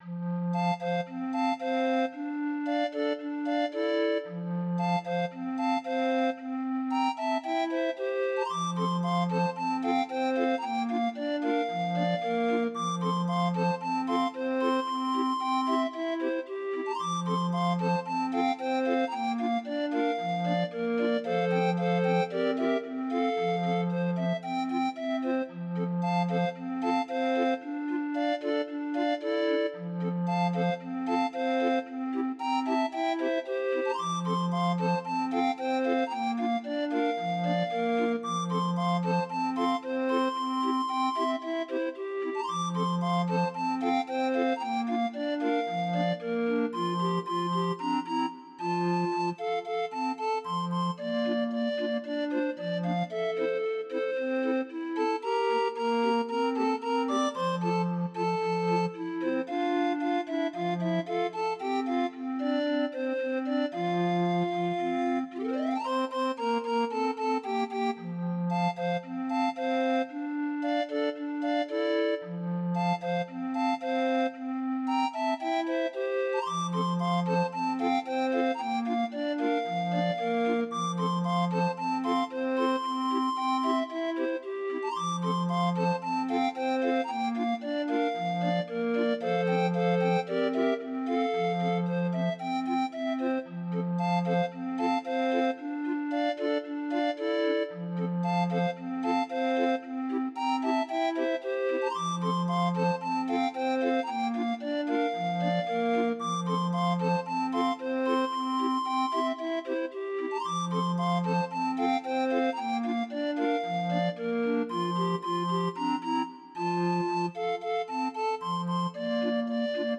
Demo of 20 note MIDI file